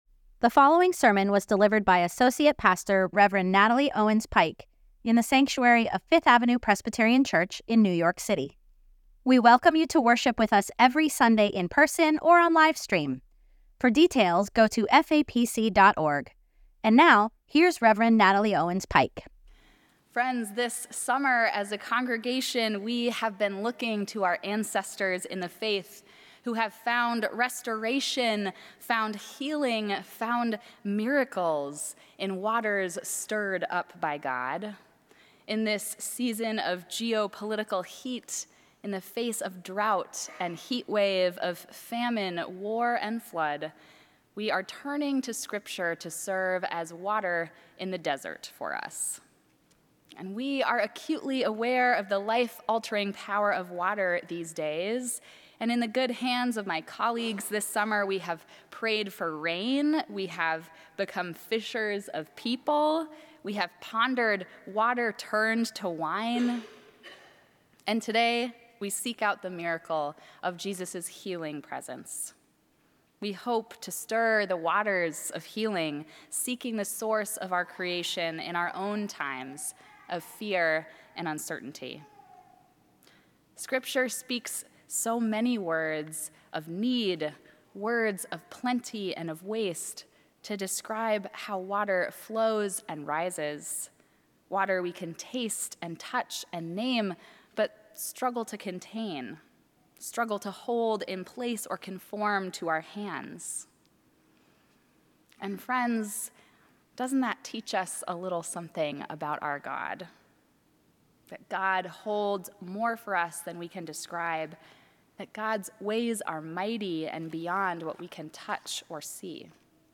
Sermons at FAPC
Weekly_sermon_podcast_file_7-27-25.mp3